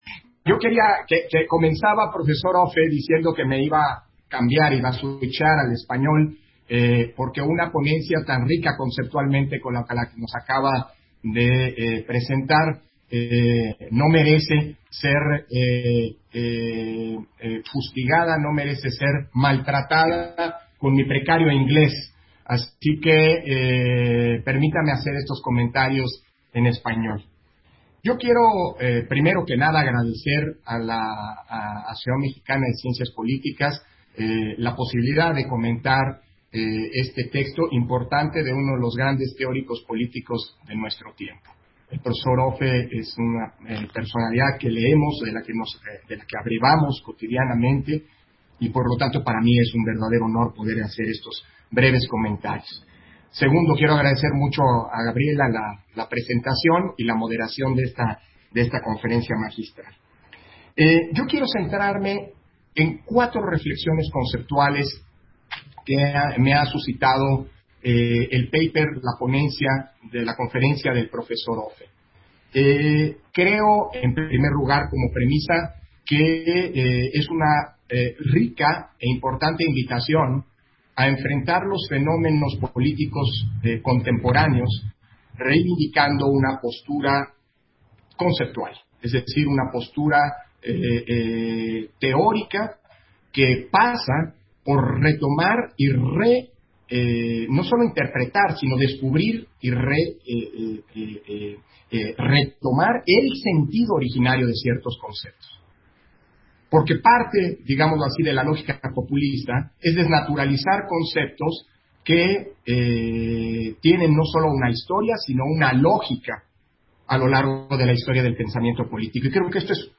Intervención de Lorenzo Córdova, en la Conferencia Magistral La voluntad del pueblo, VIII Congreso Internacional de Ciencia Política